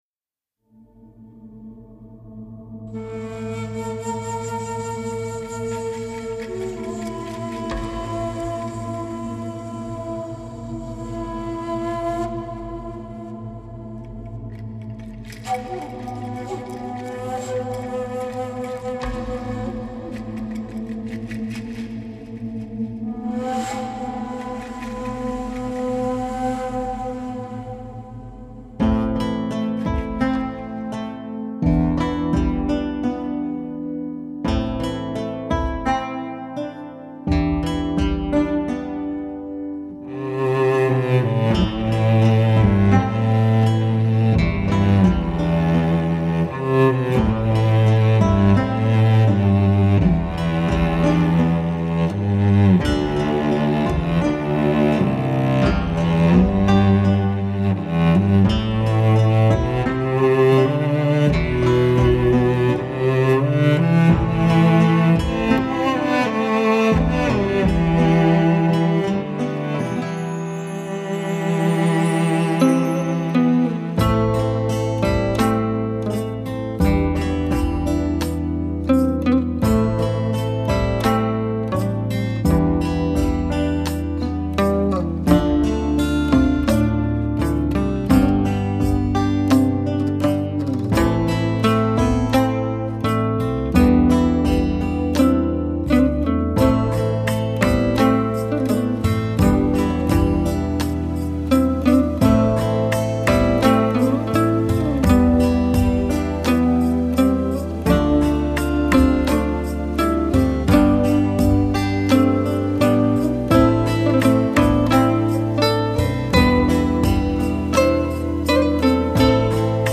& 阮与大提琴的对话
一中一西 首張阮与大提琴器乐的絕妙合作
听到中国弹拨乐阮咸的新风格
感受西洋弦乐大提琴的醇厚魅力
感受乐音在丝弦间的曼妙动态
丝音金声相融相错 明洌深邃 动容大千世界